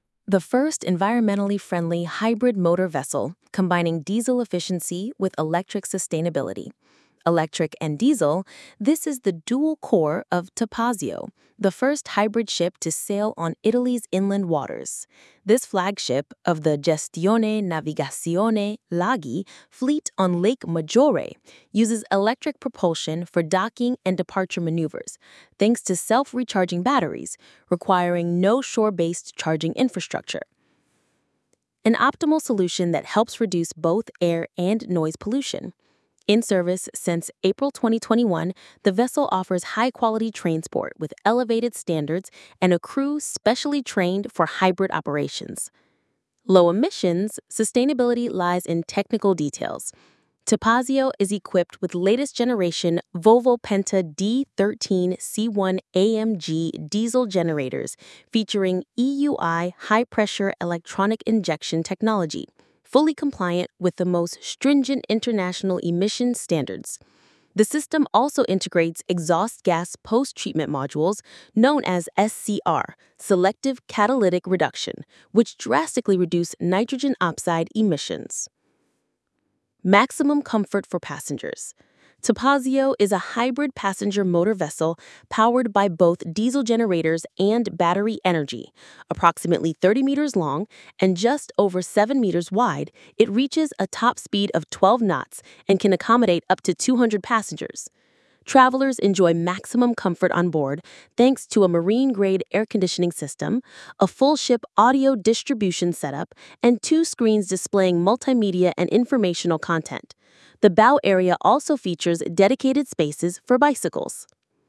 A jurney aboard the motor ship!